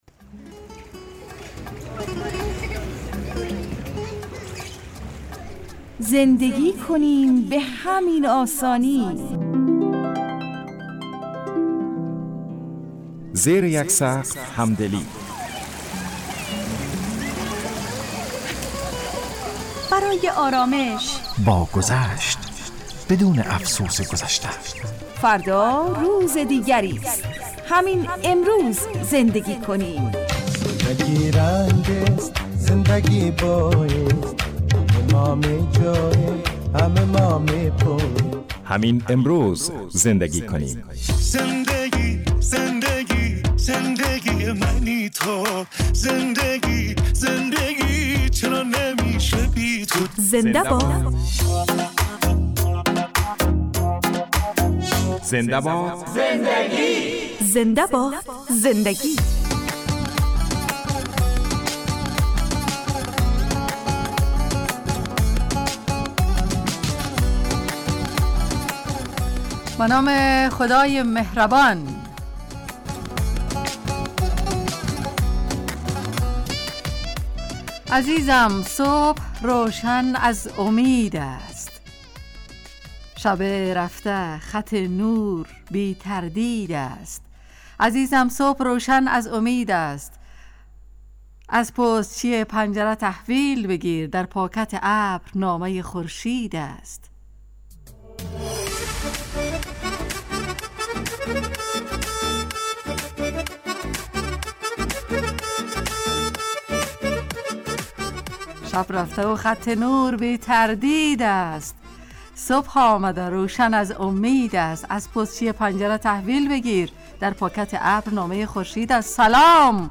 تهیه کننده و گوینده